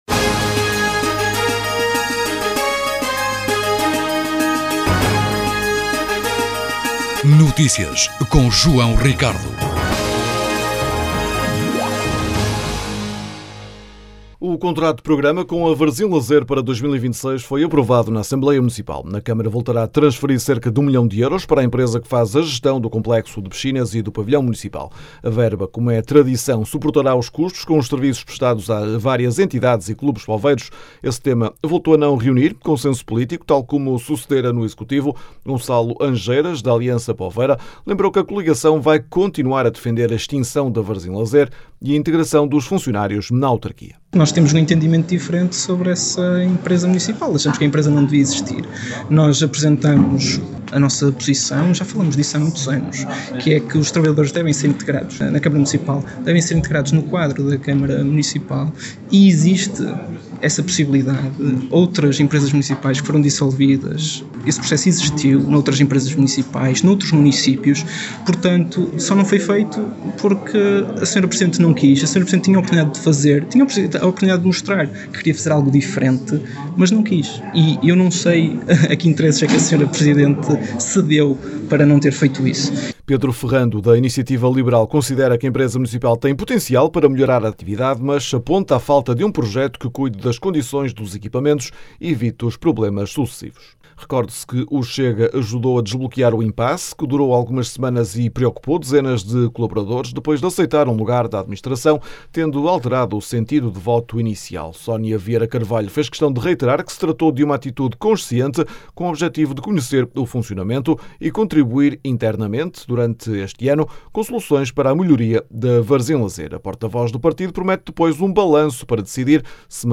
As declarações podem ser ouvidas na edição local.